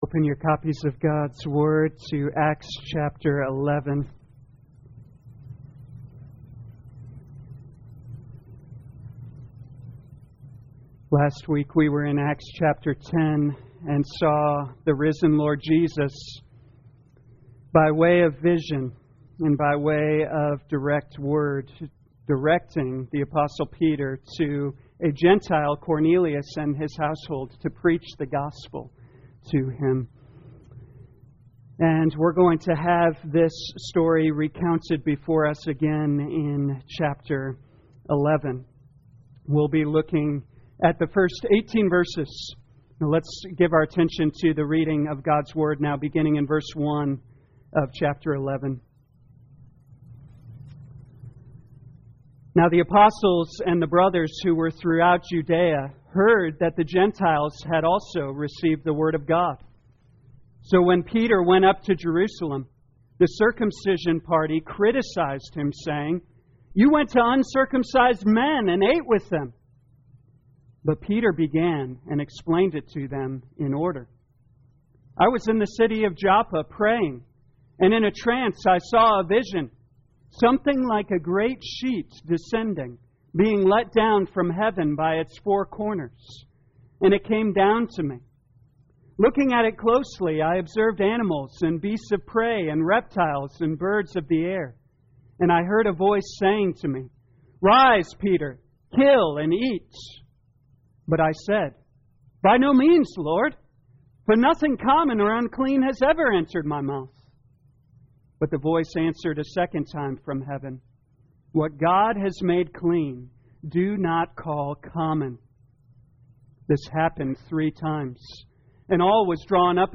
2021 Acts Morning Service Download